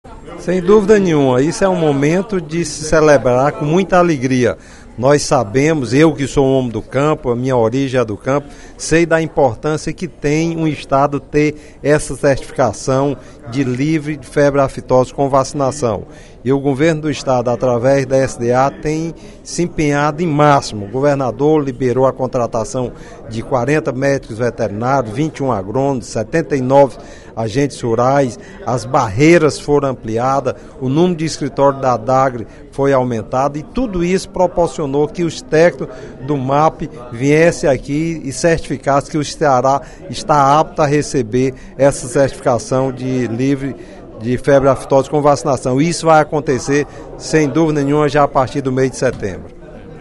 O deputado Hermínio Resende (PSL) fez pronunciamento nesta quinta-feira (29/03) na Assembleia Legislativa, para elogiar o trabalho da Secretaria do Desenvolvimento Agrário, por meio da Agência de Defesa Agropecuária do Estado do Ceará (Adagri), no controle da aftosa.